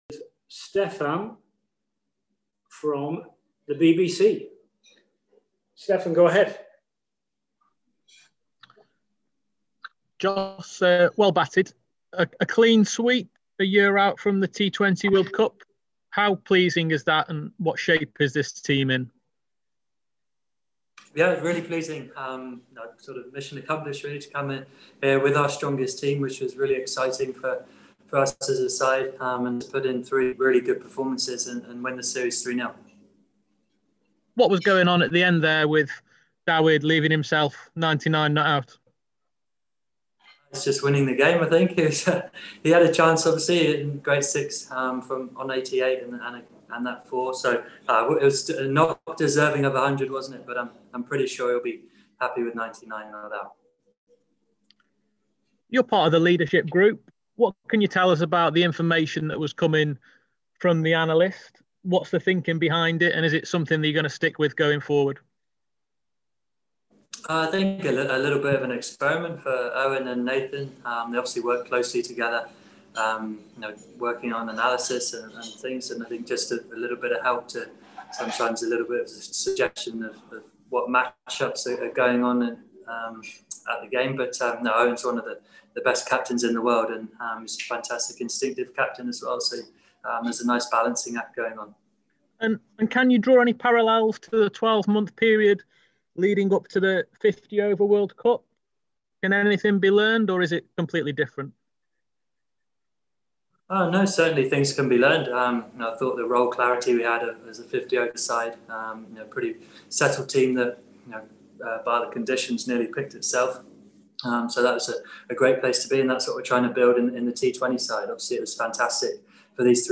England’s virtual media conference with Jos Buttler following England’s 3-0 series victory against South Africa.